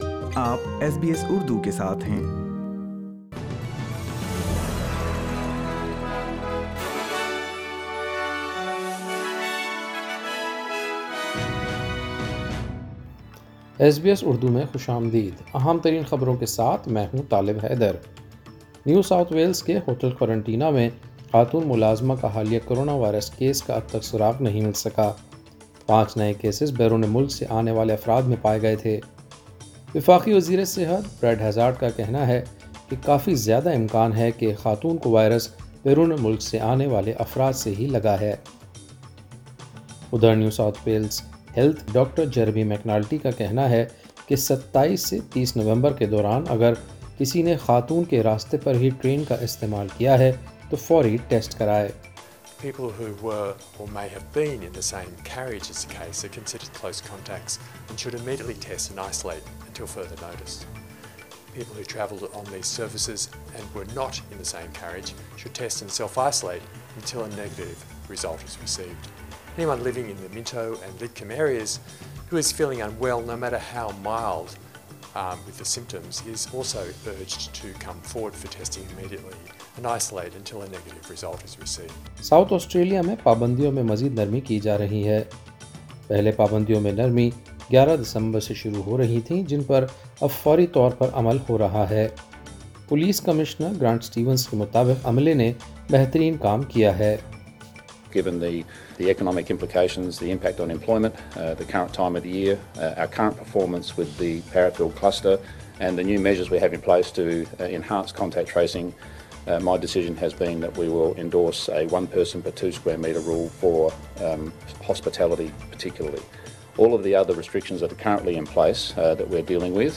سنیئے تفصیل نیوز پوڈکاسٹ میں۔